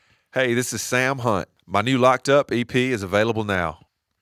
Audio / LINER Sam Hunt (available now)